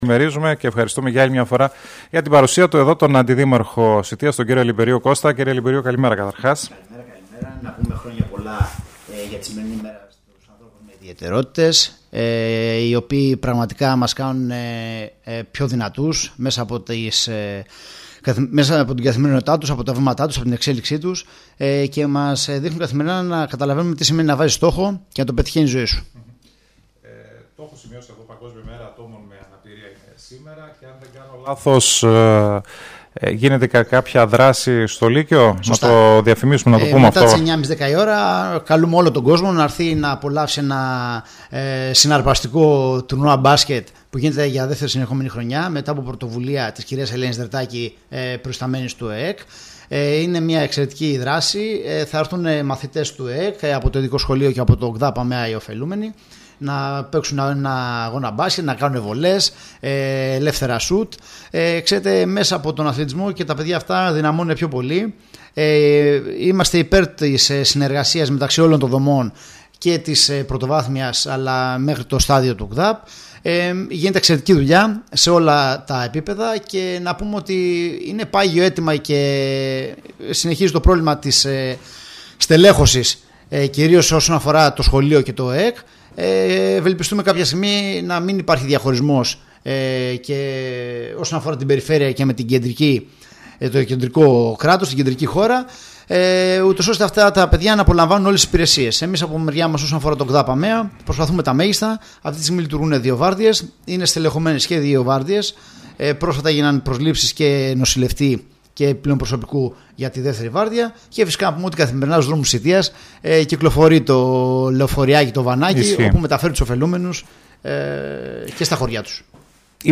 Ο Αντιδήμαρχος κ. Λυμπερίου μίλησε στον STYLE 100 για τα αθλητικά έργα, αλλά και όλα τα θέματα που απασχολούν τον δήμο(ηχητικό)